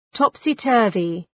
Προφορά
{,tɒpsı’tɜ:rvı}